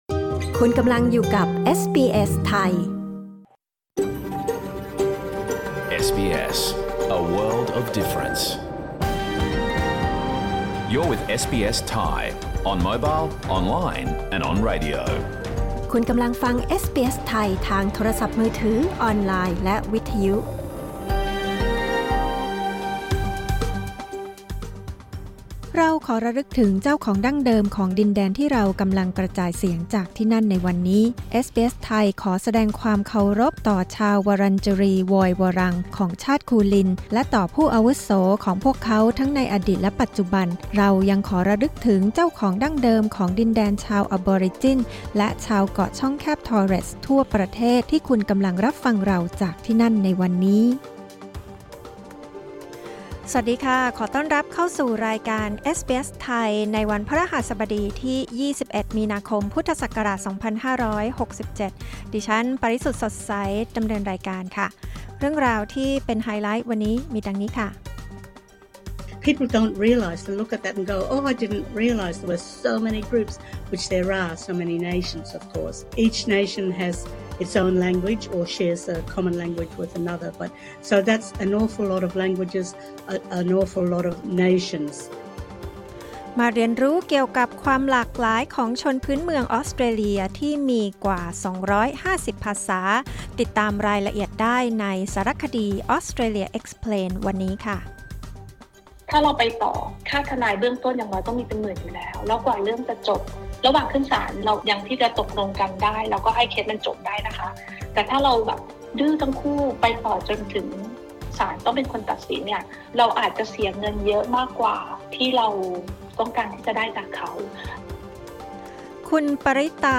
รายการสด 21 มีนาคม 2567